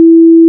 Combat (8): sword, bow, zombie_hit, zombie_death, hurt, shield, explosion, raider
**⚠  NOTE:** Music/SFX are PLACEHOLDERS (simple tones)
player_hurt.wav